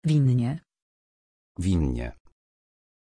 Pronunciation of Winnie
pronunciation-winnie-pl.mp3